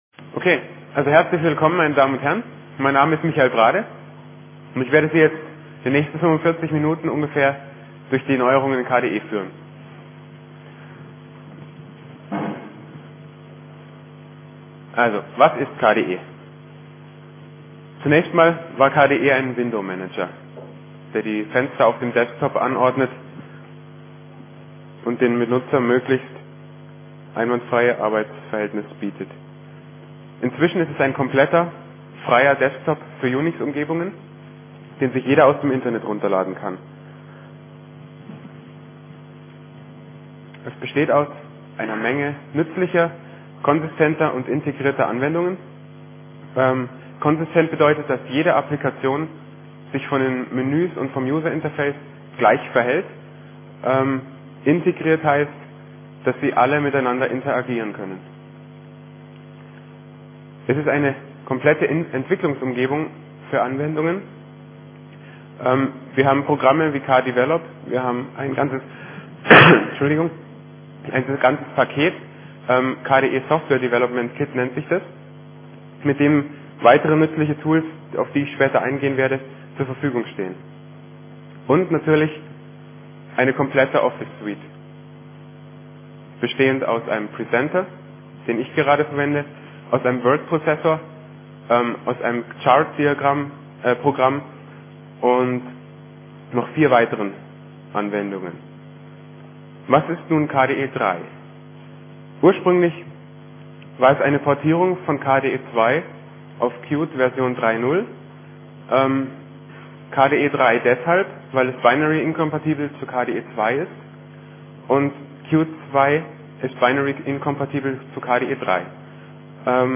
4. Chemnitzer Linux-Tag